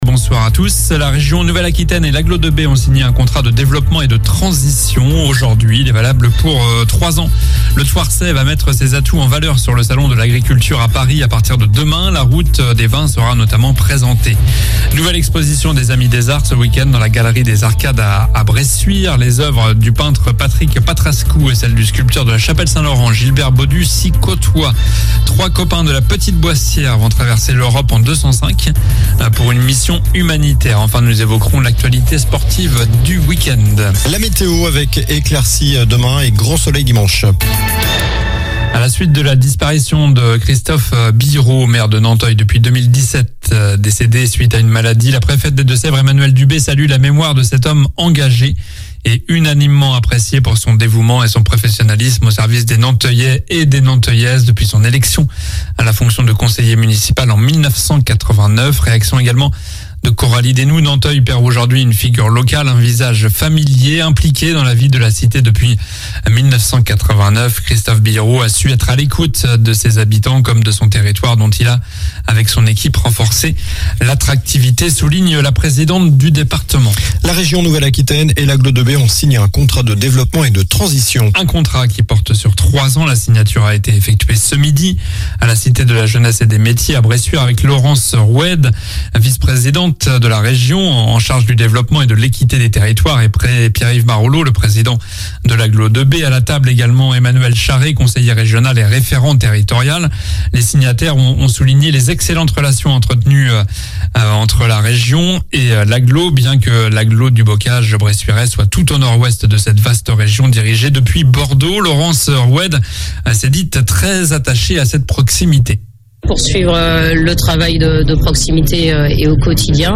Journal du vendredi 24 février (soir)